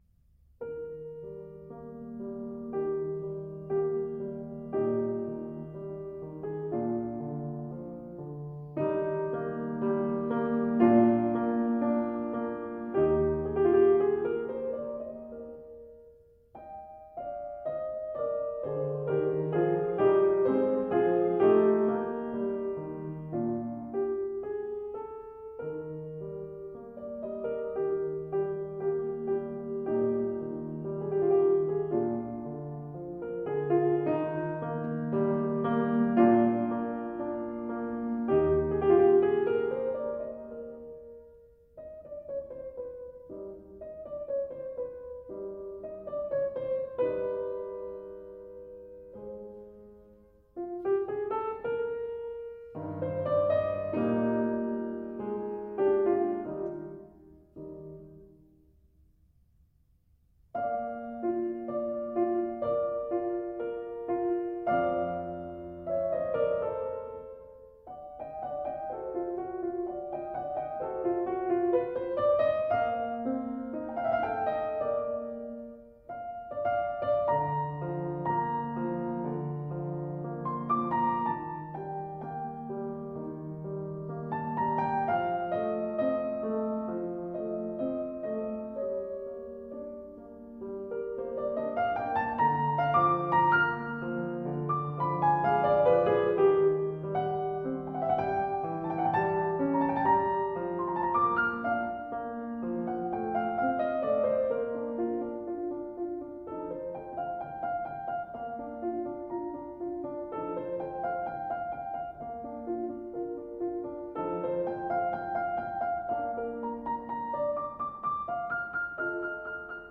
Mozart, Piano Sonata #14 In C Minor, K 457 – 2. Adagio